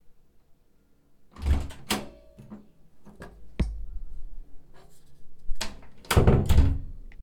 Abrir puerta, entrar y cerrar puerta
Grabación sonora en la que se capta el sonido de alguien abriendo una puerta, entrando o saliendo del lugar y cerrando dicha puerta.
Sonidos: Acciones humanas